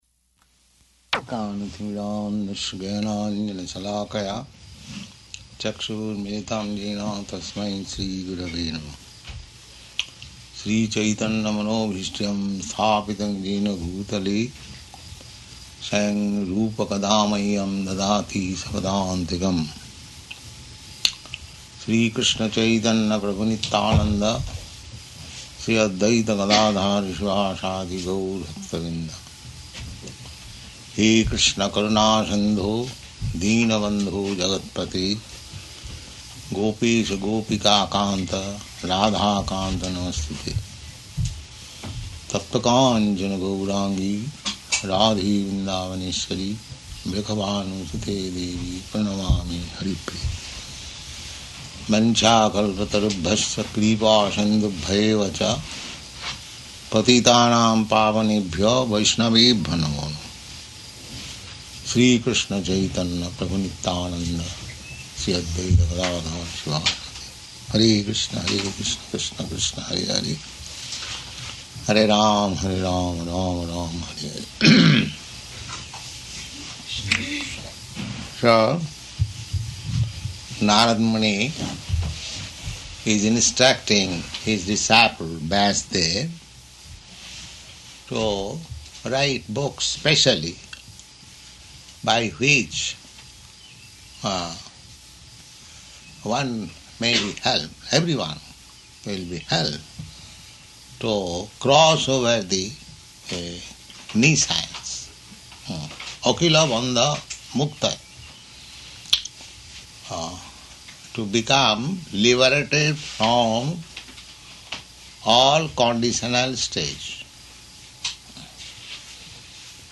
Location: New Vrindavan
[chants maṅgalācaraṇa prayers]